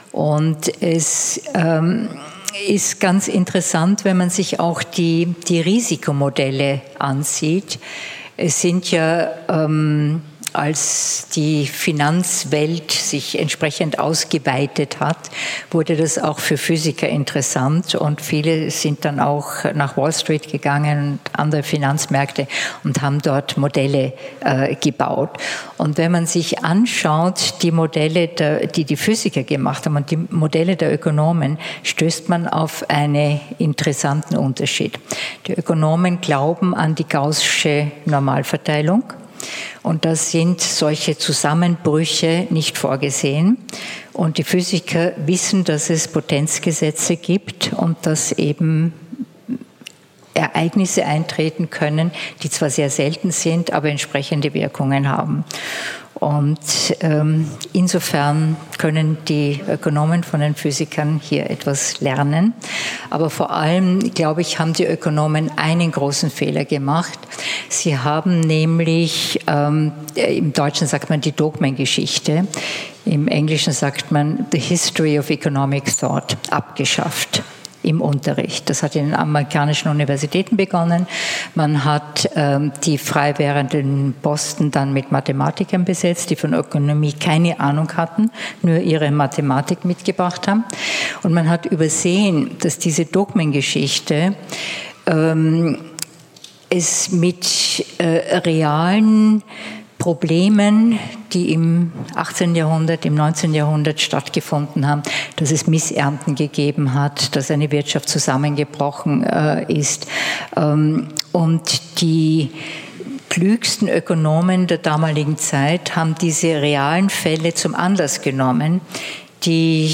Helga NOWOTNY, Former President, ERC European Research Council; Chair, ERA Council Forum Austria, hat im Rahmen einer Veranstaltung des Forum Alpbach eine sehr bemerkenswerte und beunruhigende, wenn auch nicht völlig überraschende, Aussage getätigt:
03_panel_discussion.mp3